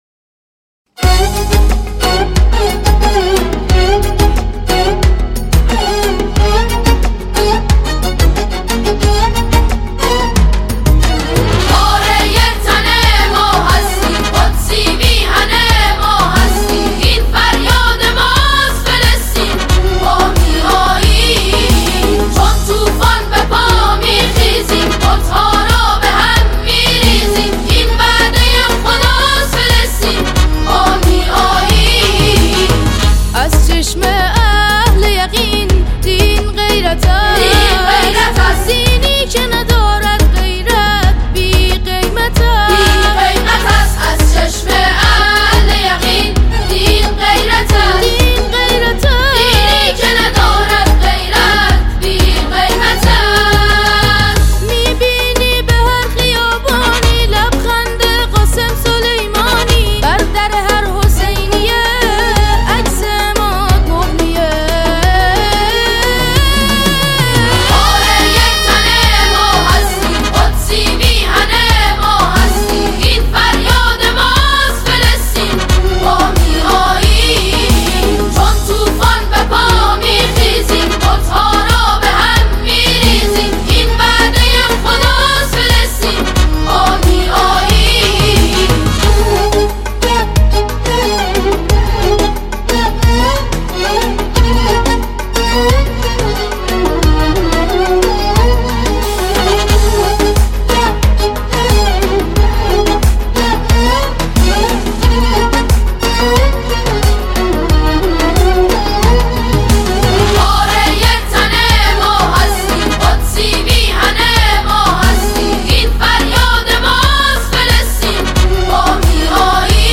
نوا